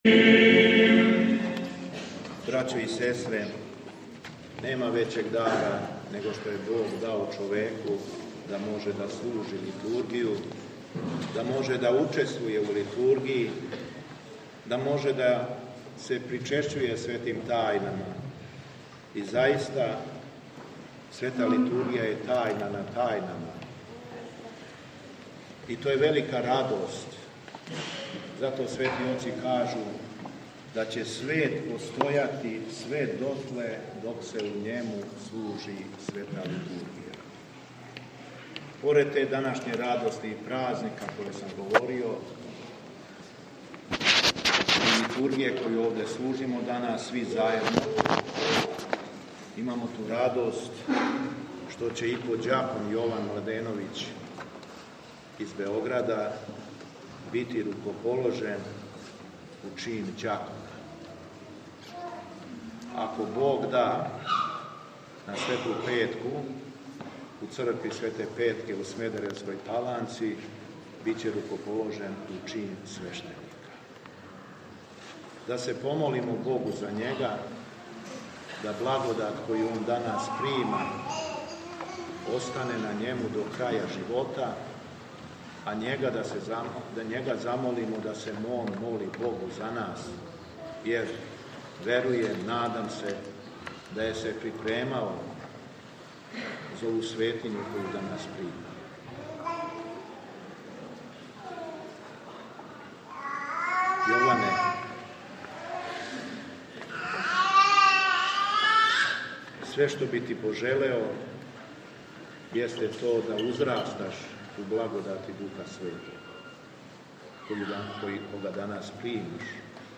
Надахнутом беседом шумадијски Првојерах беседио је окупљеним верницима:
Беседа Његовог Високопреосвештенства Митрополита шумадијског г. Јована